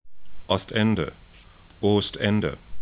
ɔst'ɛndə